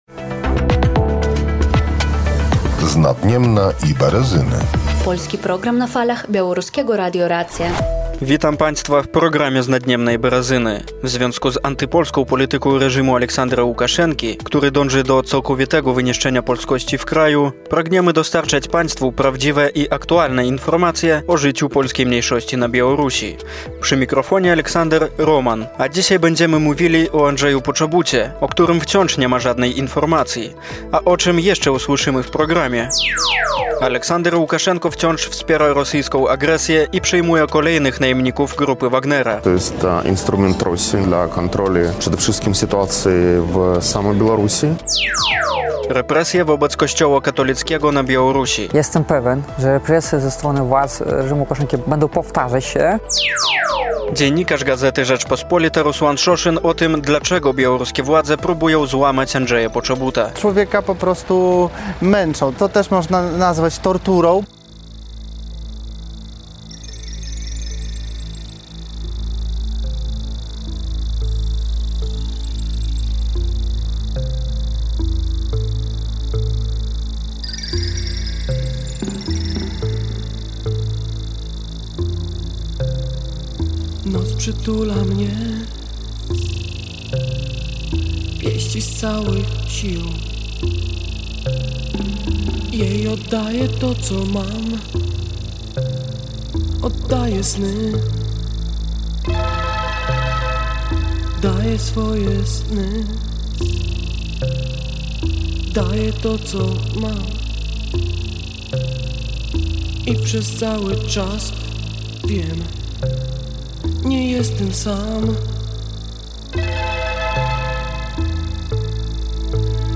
Праграма, якую рыхтуюць мясцовыя палякі з мэтай распавесці пра найбольш значныя і актуальныя падзеі з жыцця польскай меншасці ў Беларусі. Цікавыя рэпартажы, захапляльныя гістарычныя вандроўкі, а таксама гутаркі з неардынарнымі асобамі. Распавядаем дынамічна, аб’ектыўна і праўдзіва, а усё гэта – з добрай музыкай.